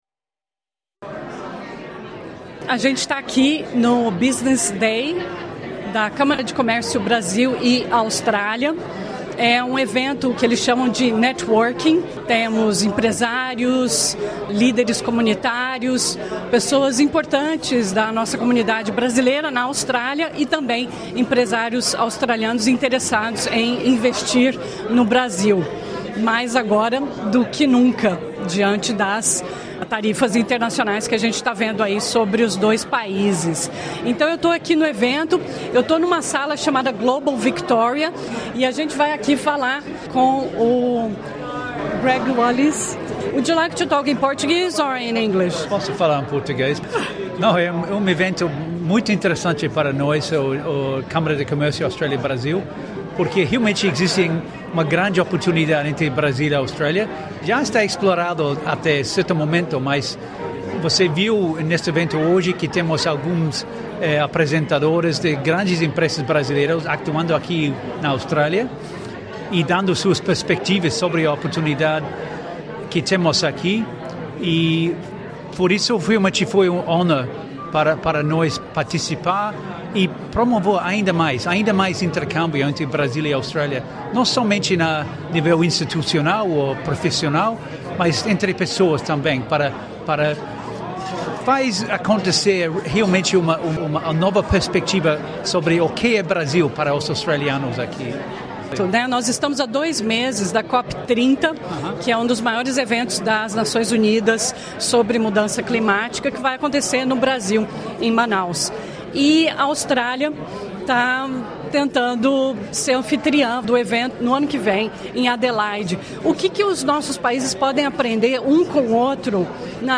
fala a empresários australianos durante o Brazil Week em Melbourne e discute uma frase que ouve com frequência